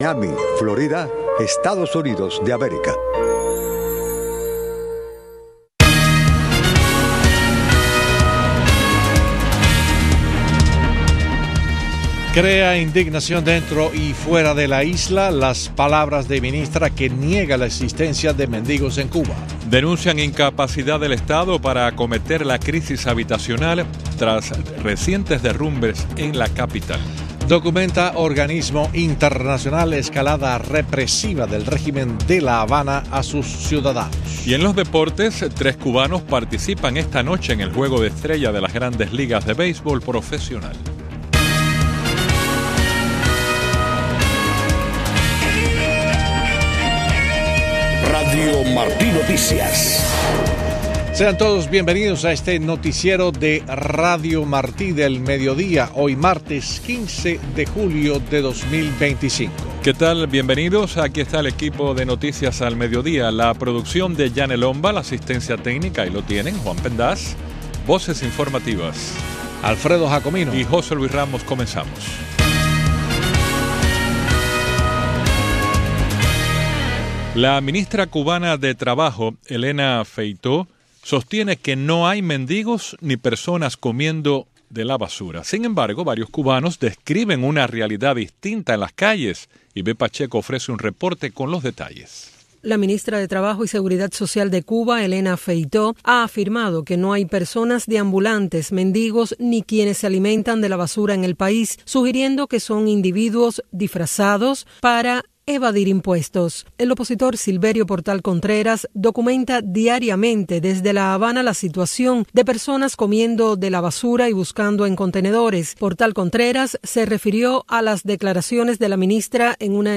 Noticiero de Radio Martí 12:00 PM